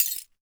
GLASS_Fragment_12_mono.wav